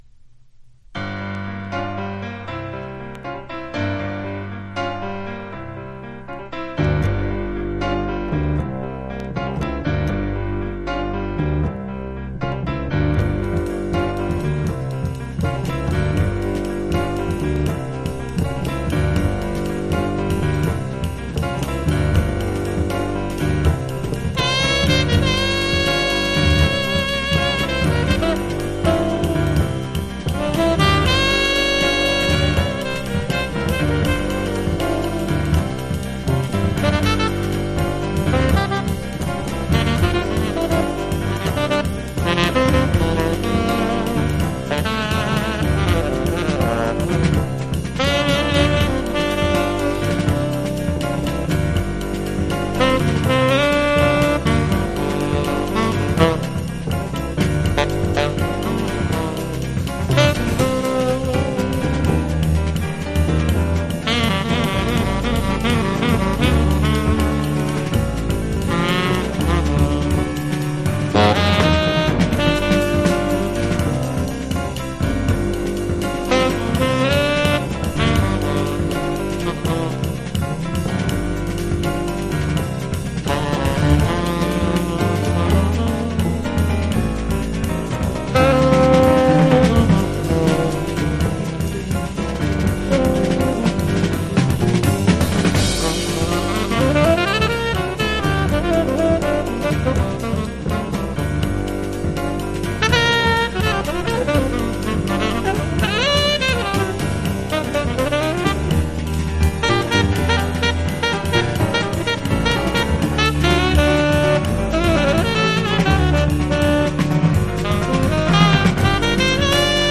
ピアノ